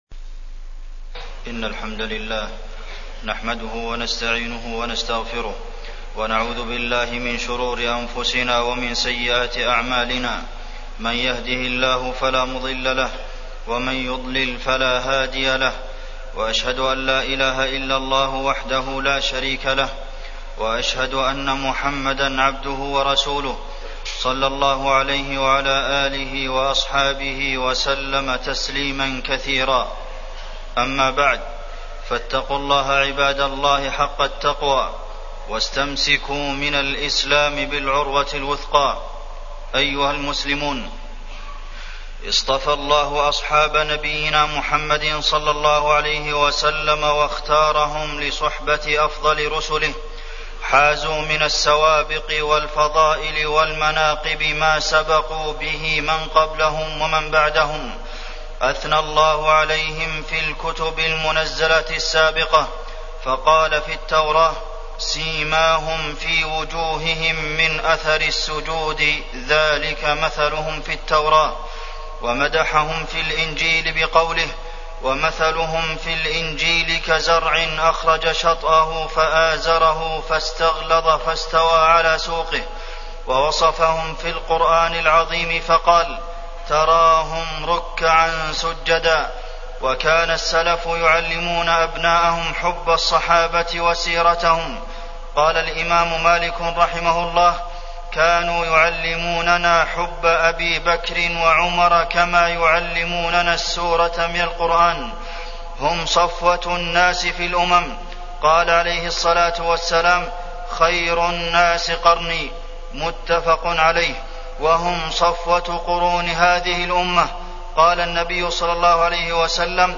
تاريخ النشر ٢٣ ذو القعدة ١٤٢٩ هـ المكان: المسجد النبوي الشيخ: فضيلة الشيخ د. عبدالمحسن بن محمد القاسم فضيلة الشيخ د. عبدالمحسن بن محمد القاسم فضائل الصحابة